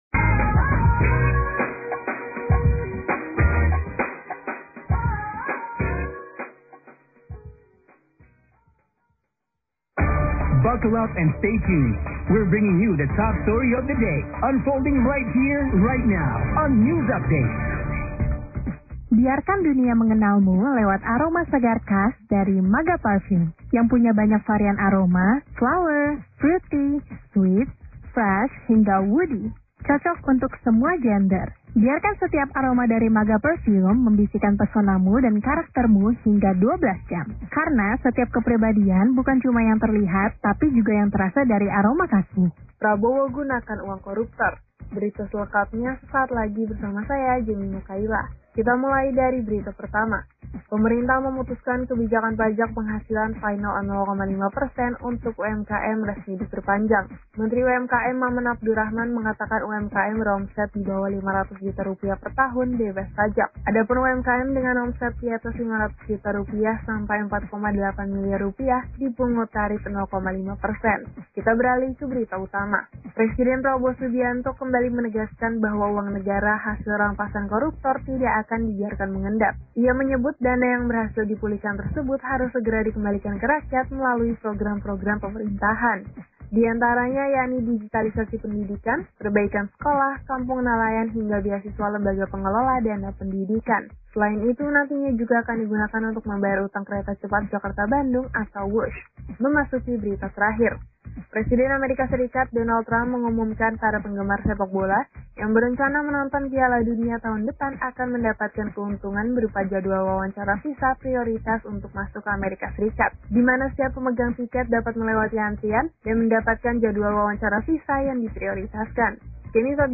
Talkshow Awareness Hipertensi Paru di Radio KIS 95.1 FM
Dalam rangka meningkatkan kesadaran masyarakat tentang penyakit Hipertensi paru bertepatan dengan Pulmonary Hypertension Awareness Month pada bulan November, YHPI didukung oleh PT. Novell Pharmaceuticall, mengadakan kegiatan Talkshow di Radio KIS 95.1 FM Jakarta pada 18 November 2025.